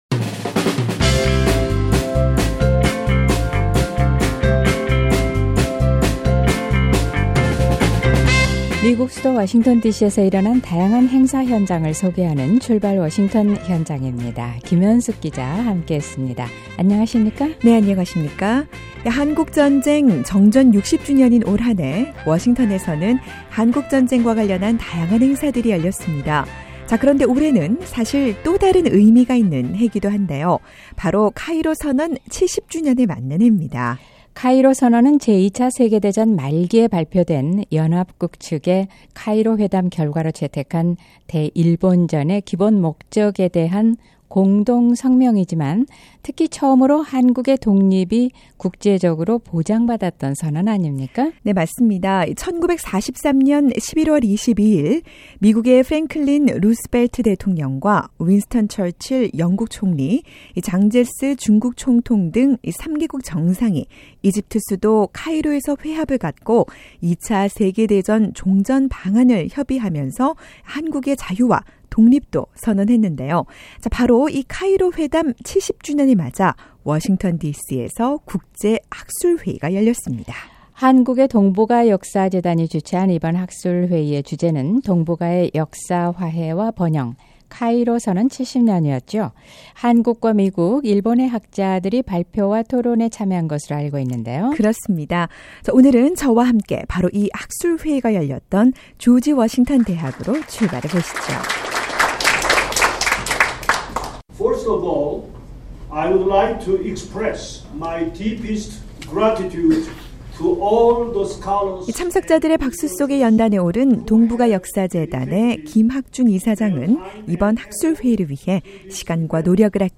오늘은 바로 이 학술회의 현장으로 출발합니다.